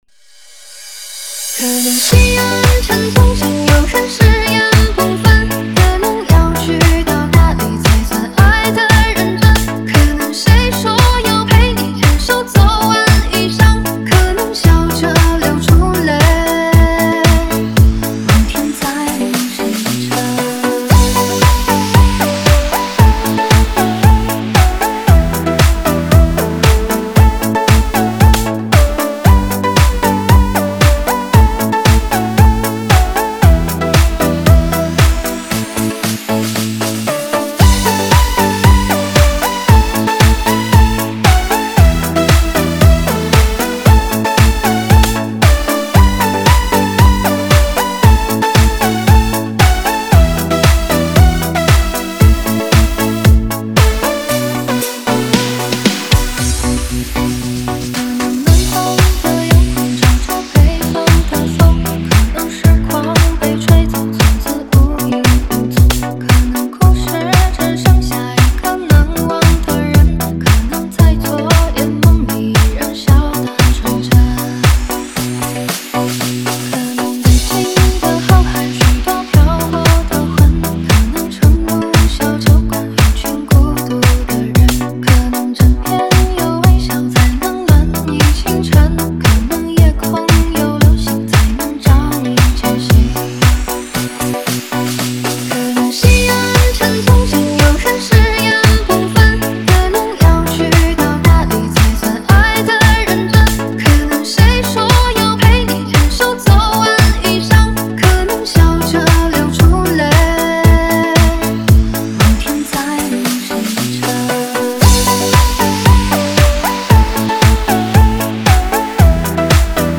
5天前 DJ音乐工程 3 推广